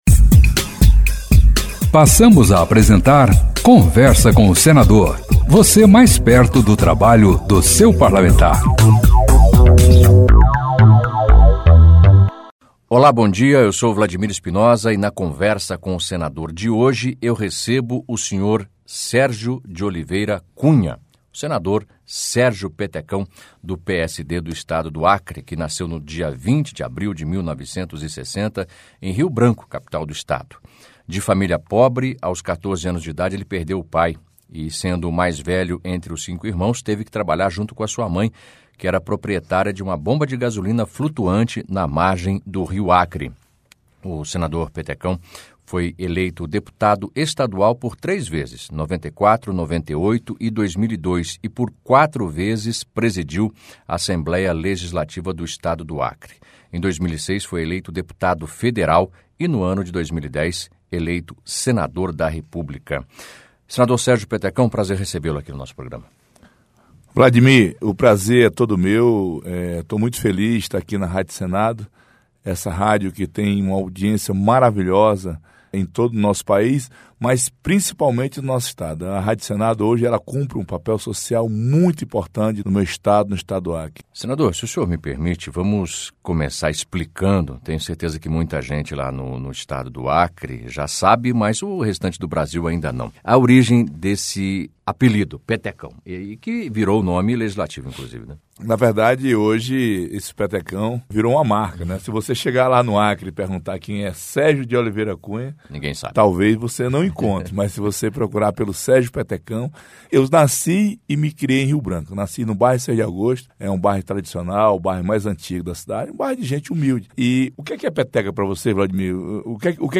Em entrevistas à Rádio Senado, senadores falam um pouco sobre sua história de vida